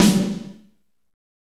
Index of /90_sSampleCDs/Northstar - Drumscapes Roland/DRM_Fast Rock/KIT_F_R Kit Wetx